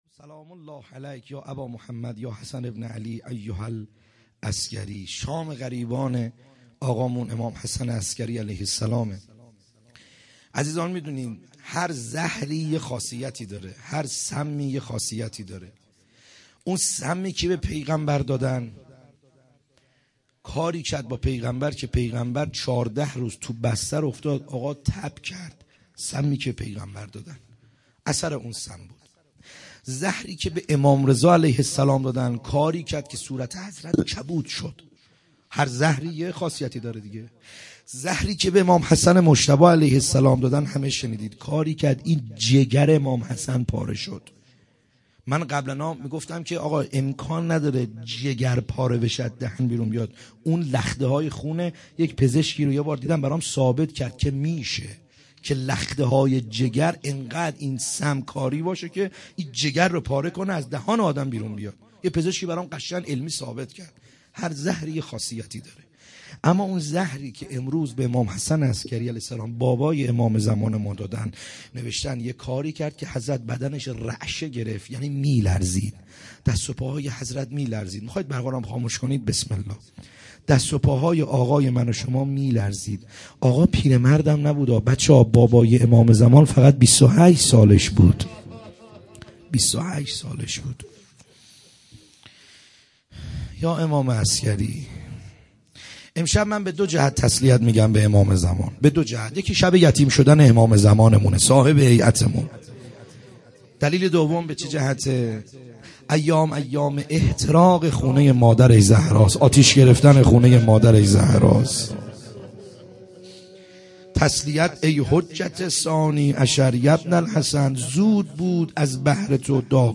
خیمه گاه - بیرق معظم محبین حضرت صاحب الزمان(عج) - روضه | امام حسن عسکری ع